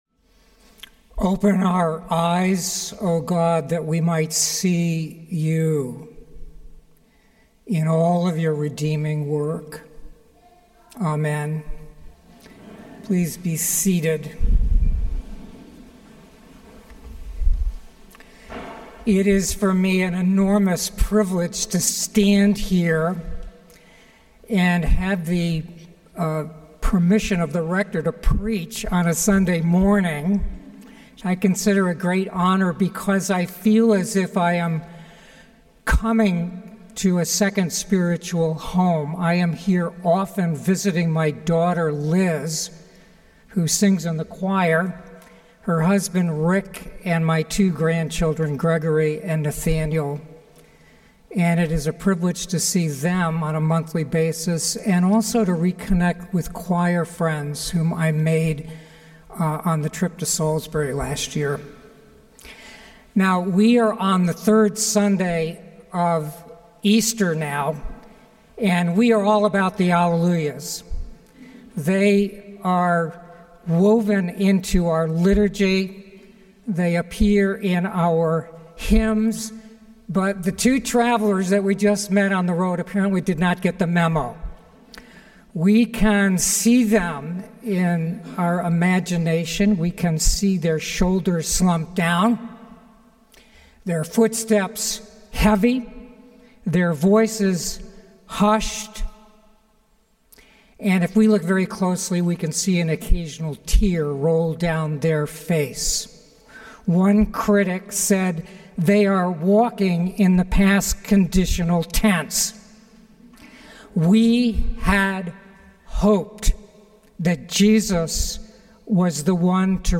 Sermons from St. Paul’s Episcopal Church, Cleveland Heights, Ohio